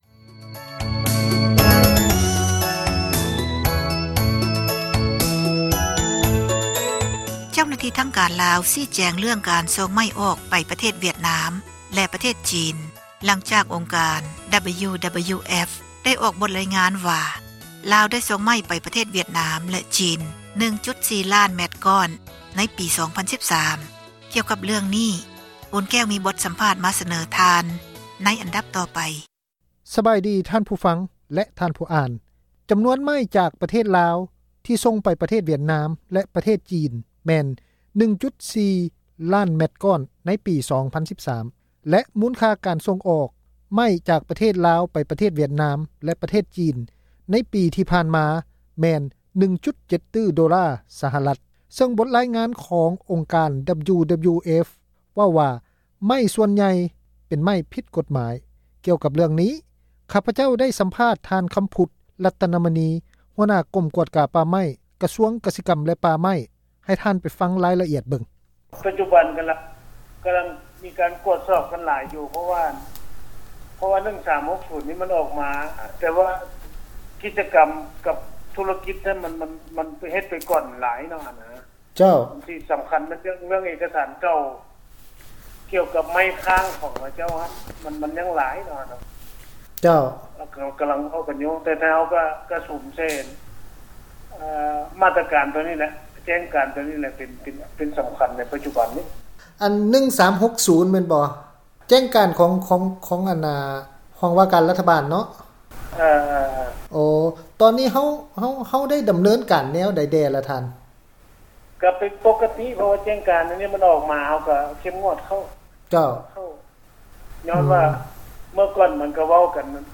ສໍາພາດທາງການລາວເຣື້ອງ ຕັດໄມ້